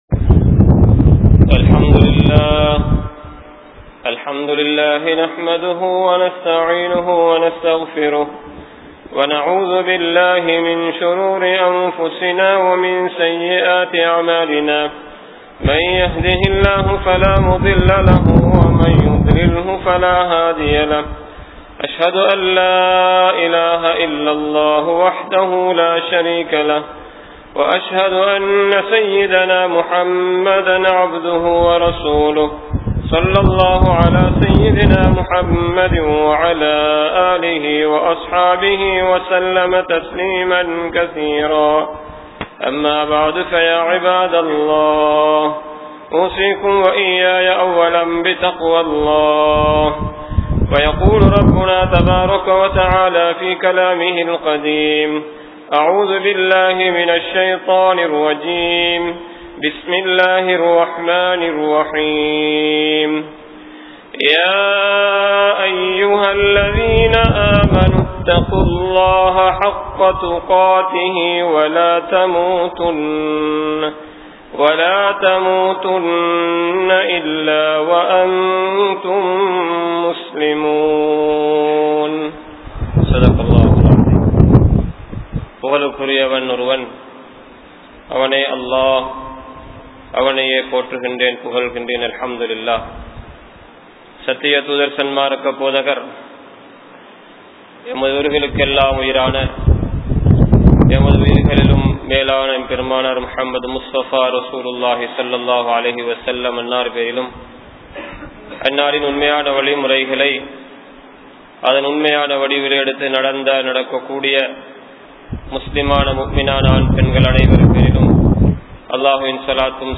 Muminin Thanmaihal (முஃமினின் தன்மைகள்) | Audio Bayans | All Ceylon Muslim Youth Community | Addalaichenai